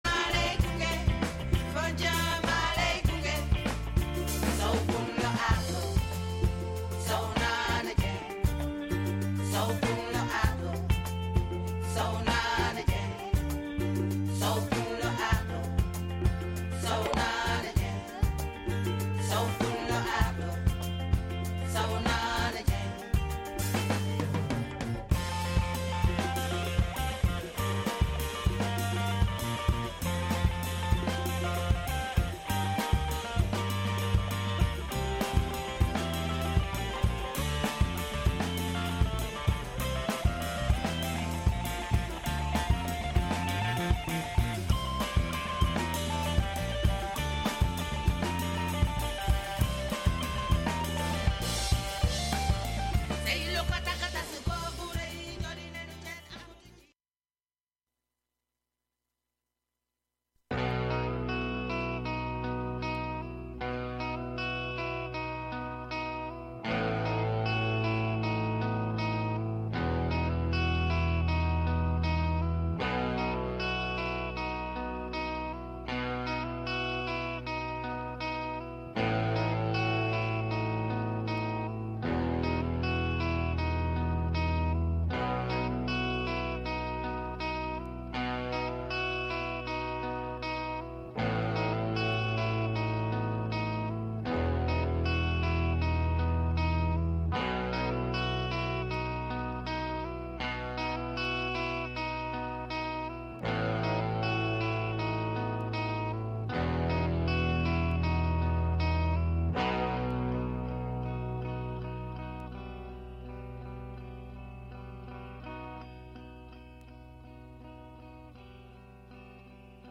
This broadcast features smooth Valentine's Day reggae